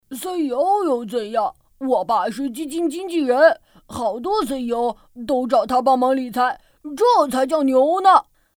女国116_动画_童声_男童2.mp3